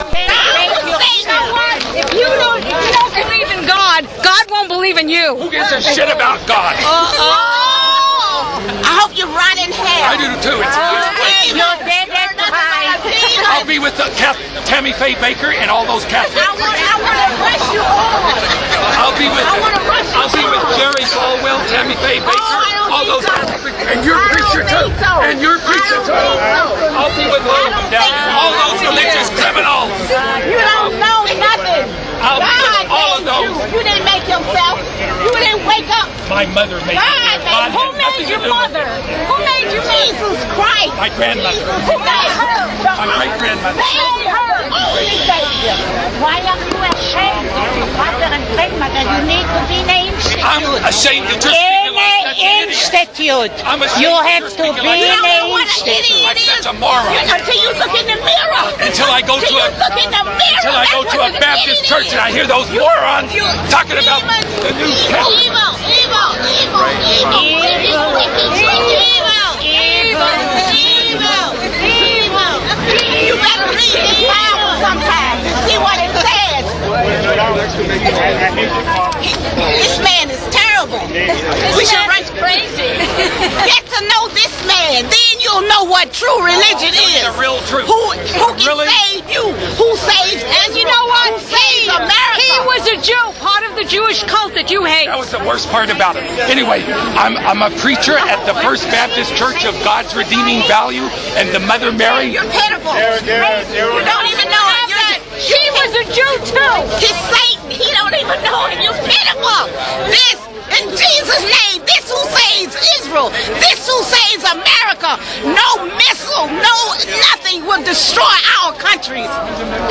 시위 현장에서의 분노에 찬 언쟁의 오디오 파일